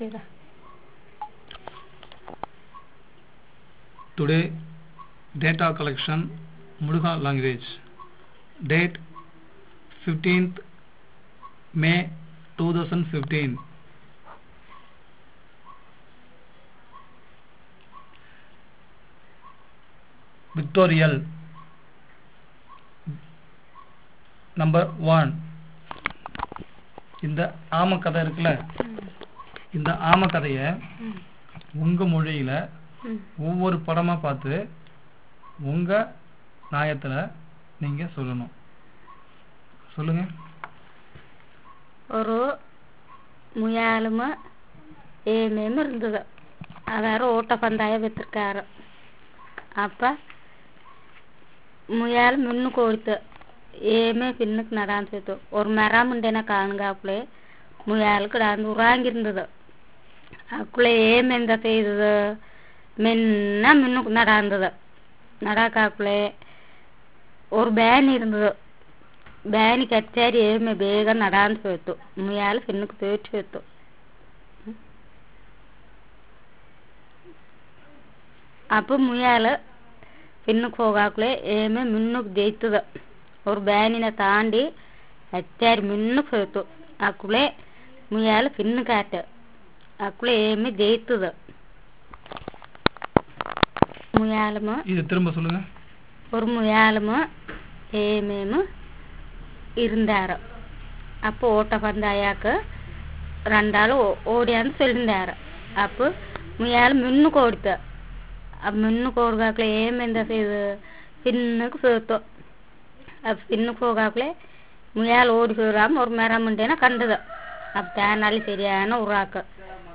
Retelling of four stories using pictures
The principal investigator requests the consultant to retell the story by looking at the pictures of the story. The stories narrated are those of the hare and the tortoise, the crow and the pot, the monkey and the cap-seller, and the woodcutter. The third and the fourth stories are attempted multiple times.